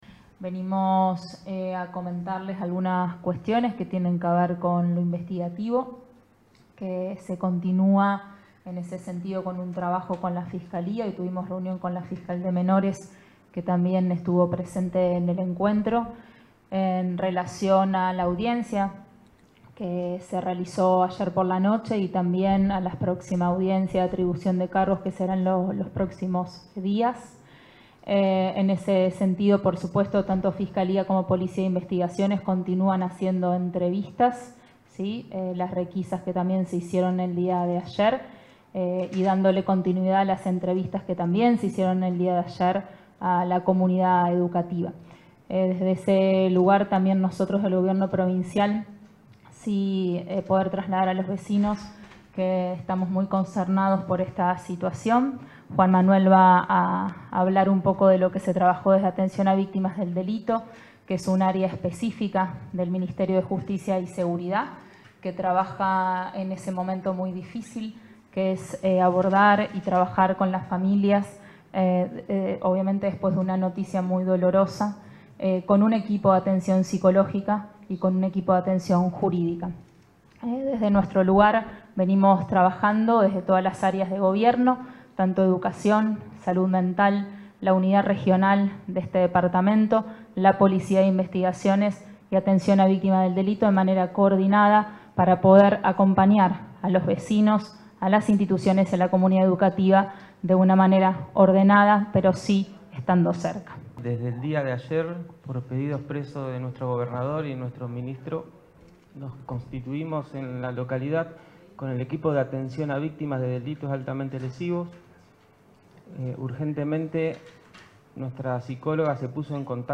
En ese contexto, este martes, las secretarias de Gestión Institucional del Ministerio de Justicia y Seguridad, Virginia Coudannes, y de Gestión Territorial del Ministerio de Educación, Daiana Gallo Ambrosis, junto al senador departamental, Felipe Michlig, brindaron detalles de los protocolos y las acciones ejecutadas hasta el momento.
Acompañaron la conferencia, el subsecretario de Gestión Institucional del Ministerio de Justicia y Seguridad, Juan Manuel Musuruana y el delegado de la Regional IX de Educación con jurisdicción en San Cristóbal, Maximiliano Rodríguez.